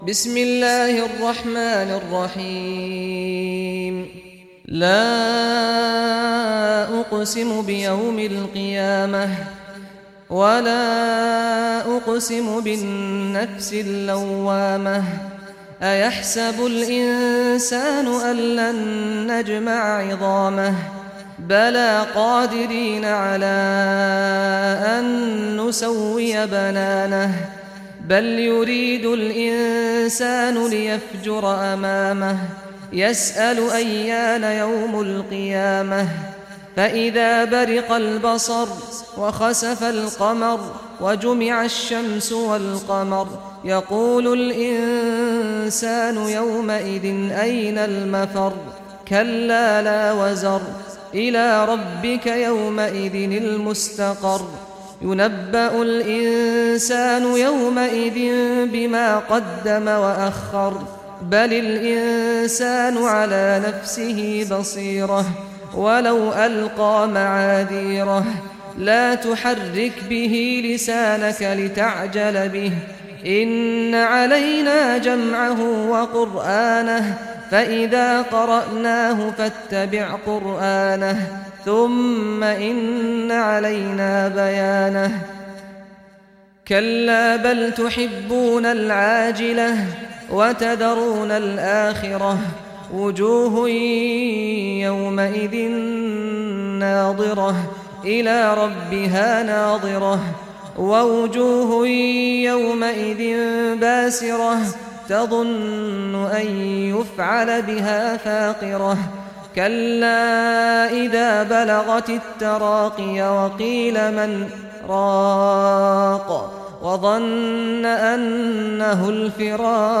دانلود سوره القيامه mp3 سعد الغامدي (روایت حفص)